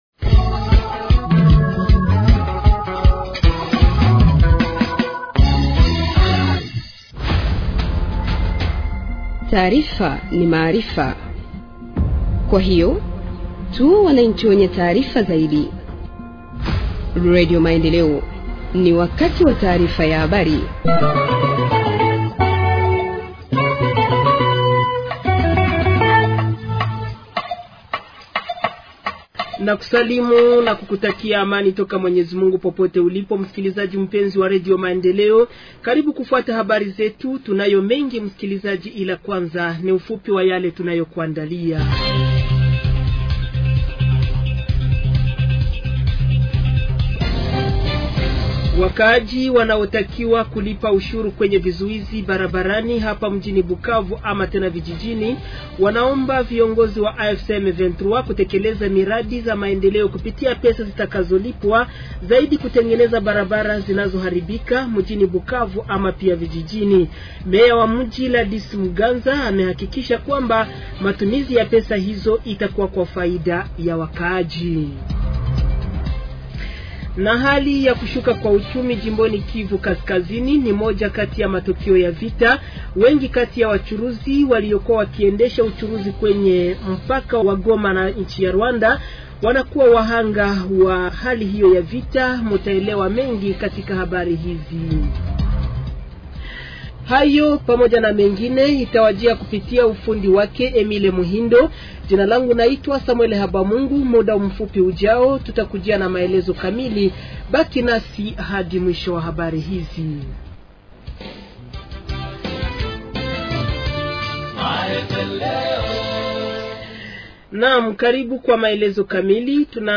Journal en Swahili du 22 avril 2025 – Radio Maendeleo